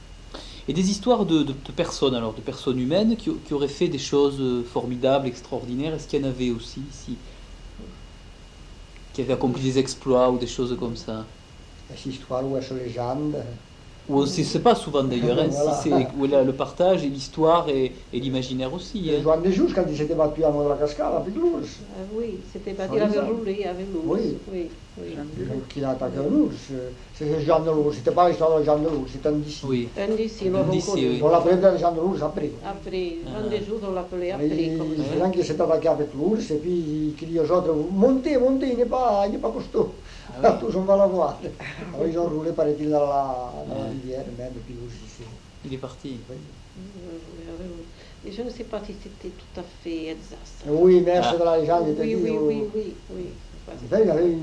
Lieu : Aulus-les-Bains
Genre : conte-légende-récit
Type de voix : voix de femme
Production du son : parlé